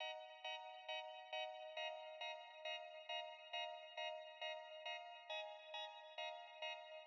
MB - Loop 4 - 68BPM.wav